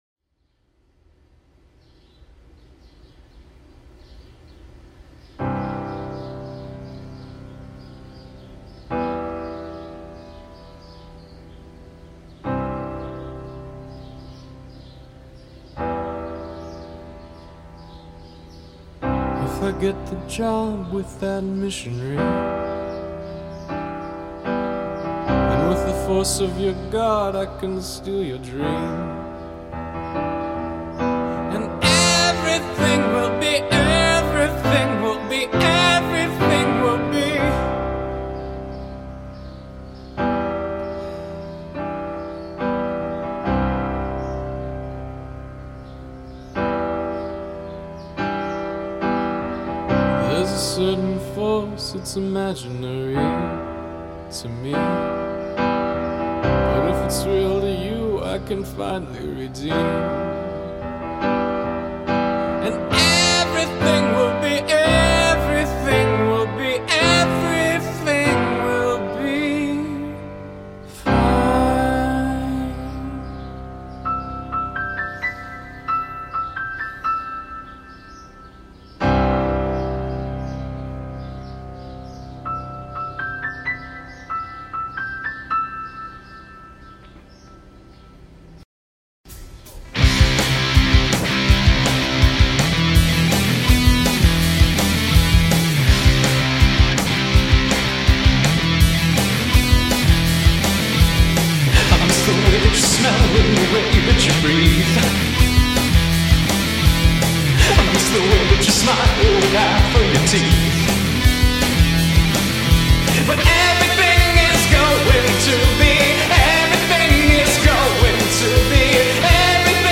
A very jangly, lo-fi, punk rock album
Gang vocals